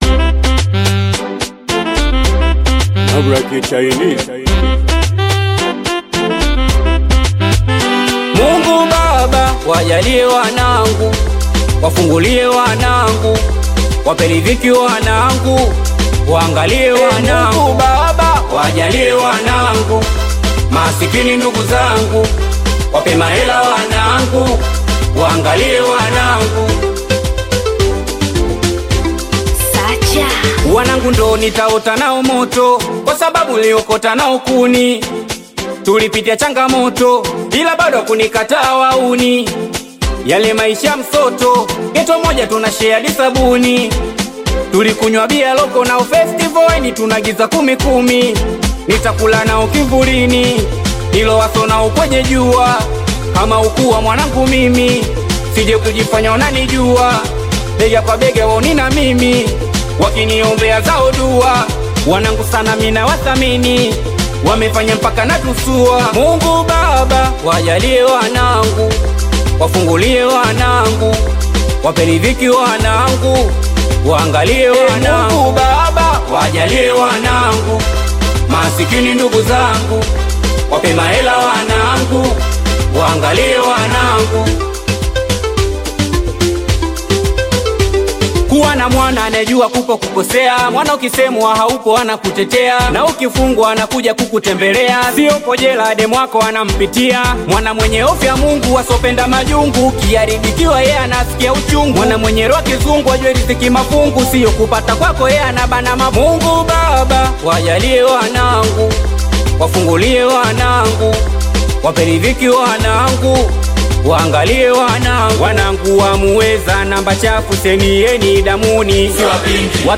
Singeli music track
Singeli song